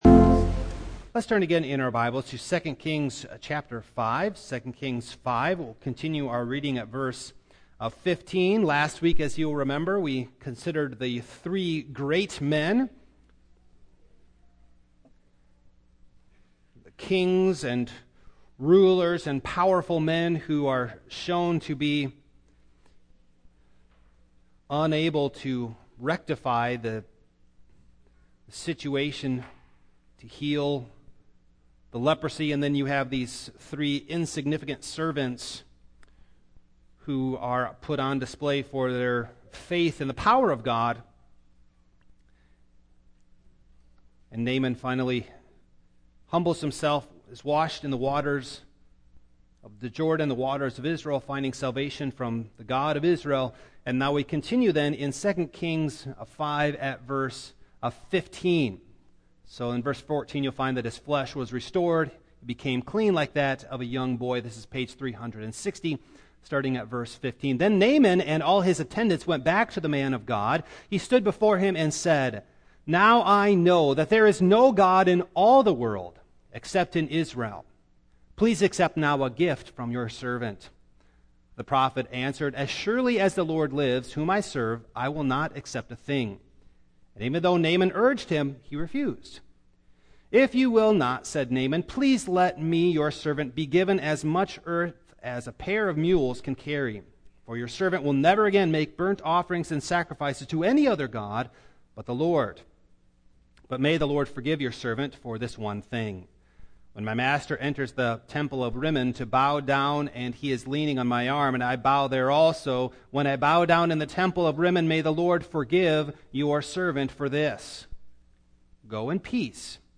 Elijah and Elisha Passage: 2 Kings 5:15-27 Service Type: Morning Download Files Notes « Seeking the Kingdom of God Elisha the Cook?